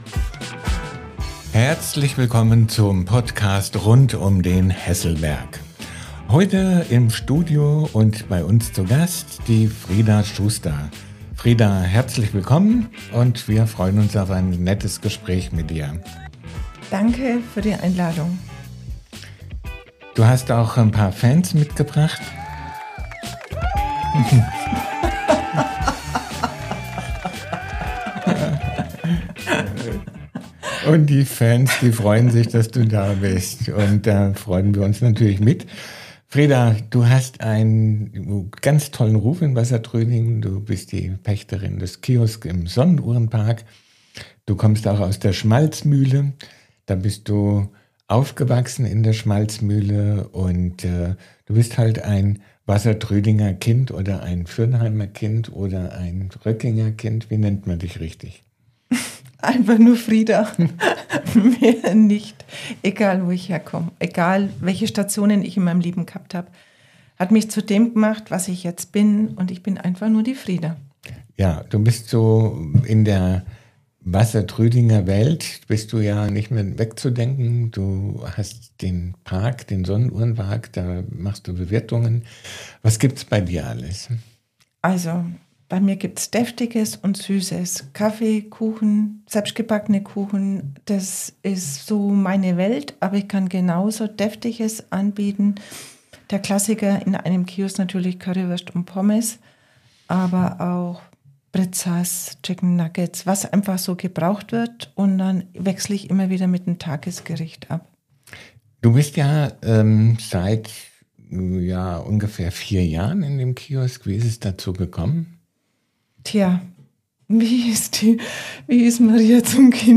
Kurzinterview